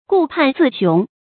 顧盼自雄 注音： ㄍㄨˋ ㄆㄢˋ ㄗㄧˋ ㄒㄩㄥˊ 讀音讀法： 意思解釋： 左看右看，自以為了不起。